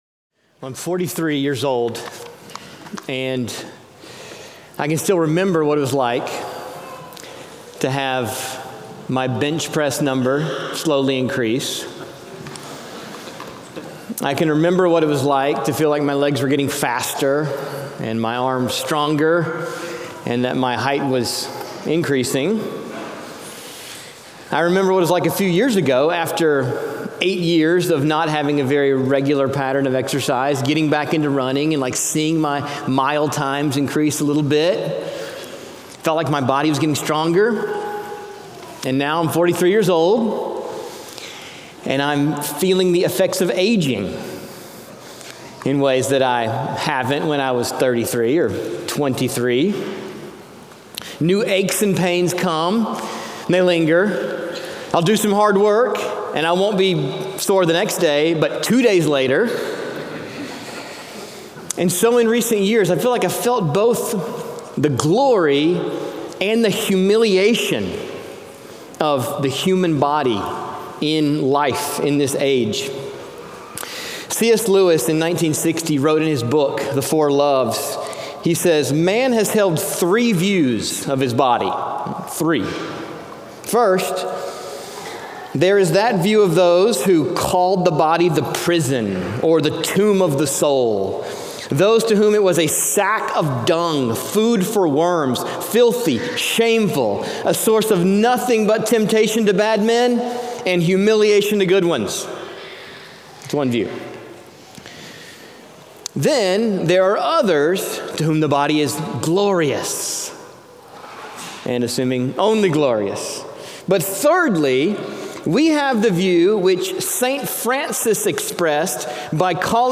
Cities Church | Saint Paul